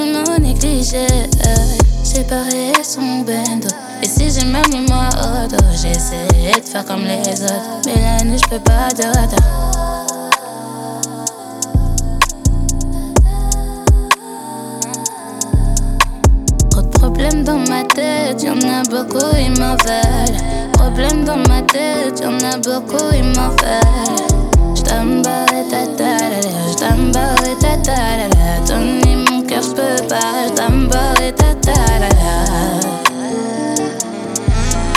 Жанр: Африканская музыка / Поп
# Afro-Pop